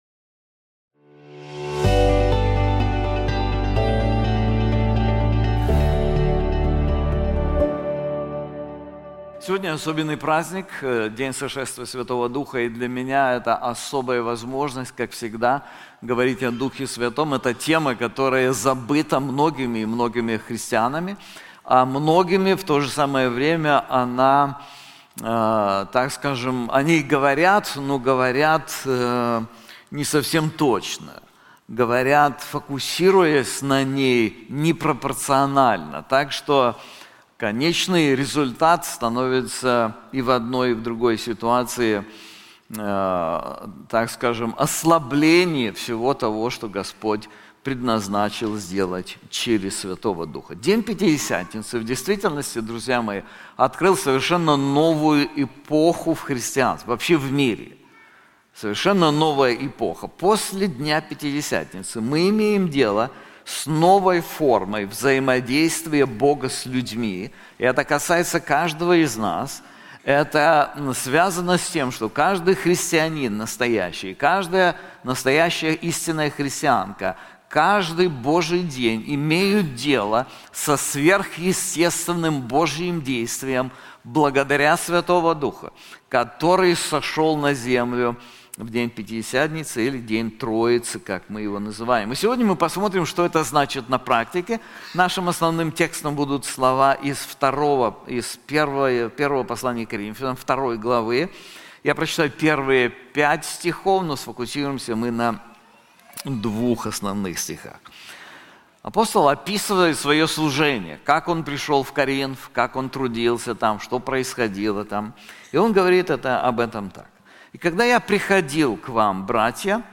Об этом в проповеди.